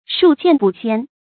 數見不鮮 注音： ㄕㄨㄛˋ ㄐㄧㄢˋ ㄅㄨˋ ㄒㄧㄢˇ 讀音讀法： 意思解釋： 數：屢次；鮮：新殺的禽獸，引伸為新鮮。